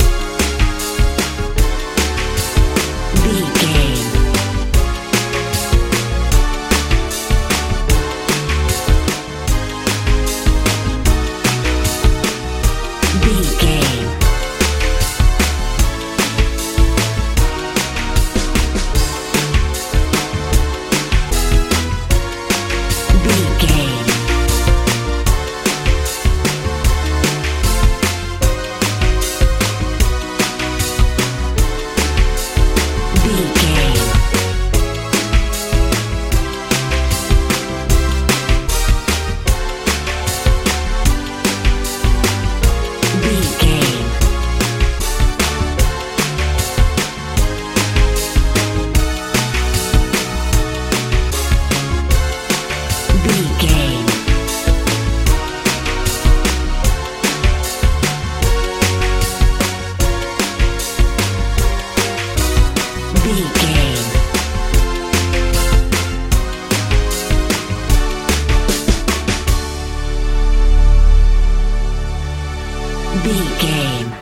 modern pop feel
Ionian/Major
C♯
energetic
happy
piano
synthesiser
bass guitar
drums
80s
90s